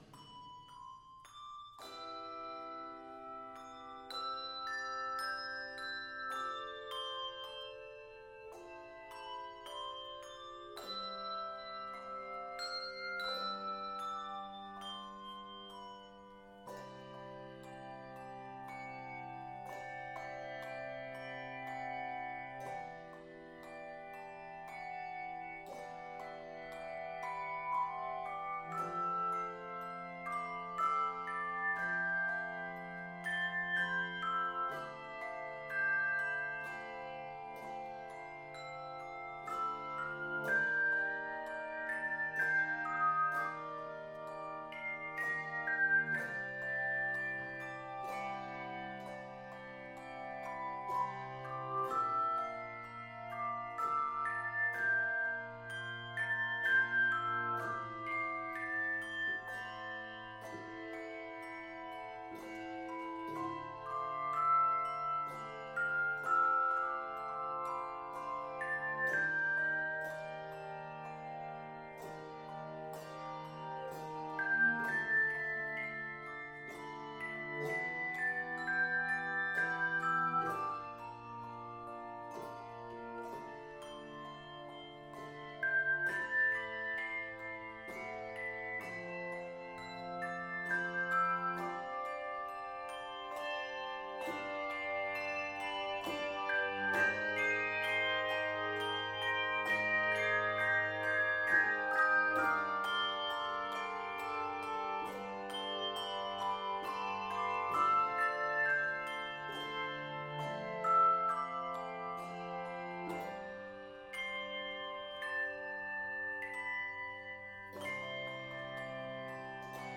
Key of C Major.
Octaves: 3-6